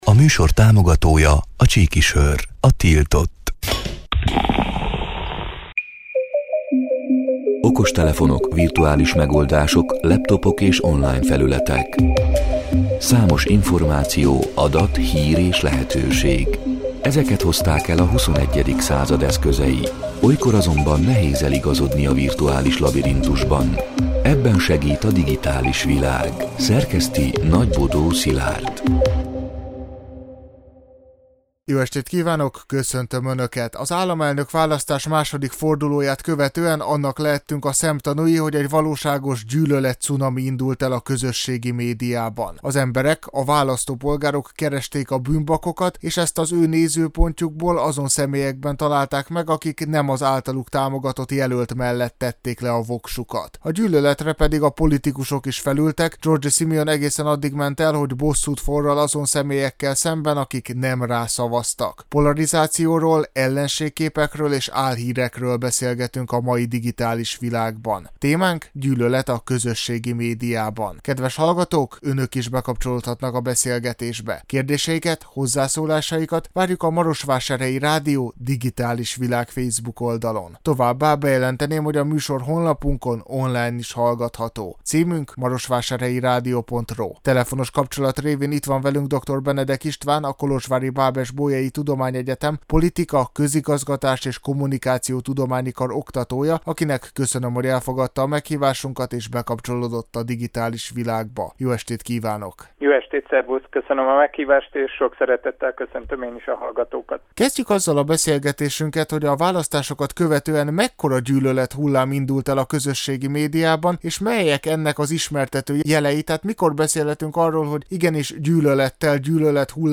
A Marosvásárhelyi Rádió Digitális Világ (elhangzott: 2025. május 27-én, kedden este nyolc órától élőben) c. műsorának hanganyaga: